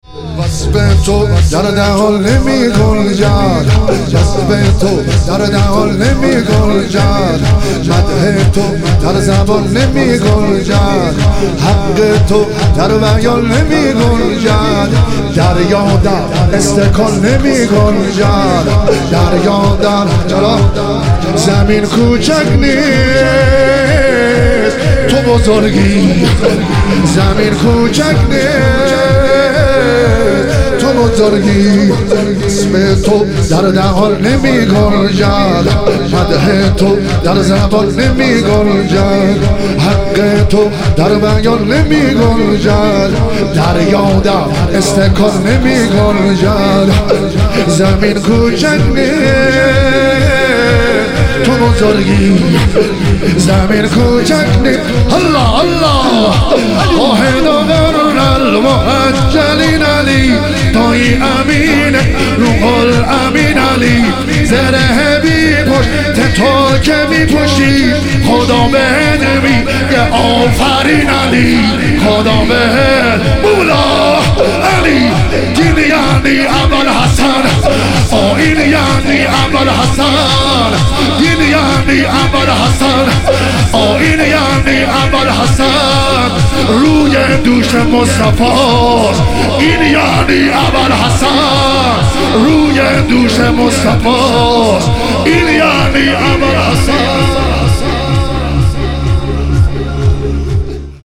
تخریب بقیع - شور - 7 - 1403